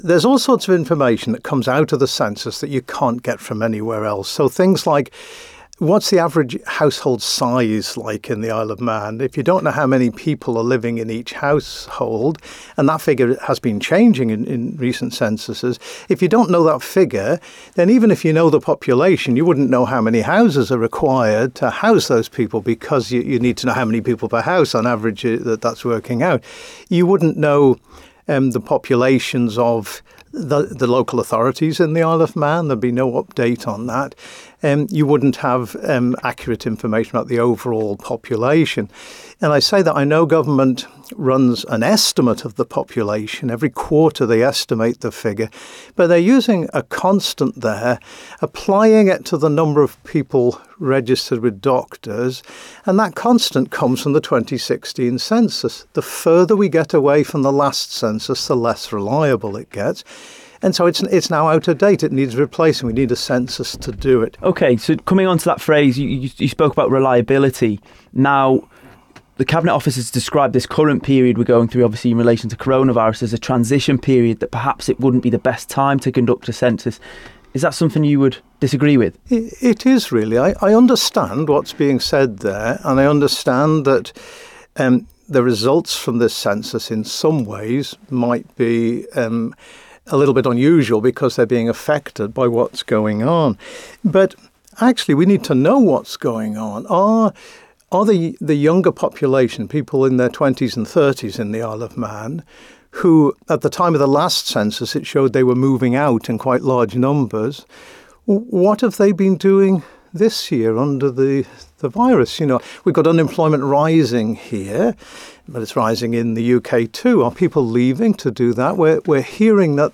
Virus impact makes it a 'crucial time' to get data, argues statistician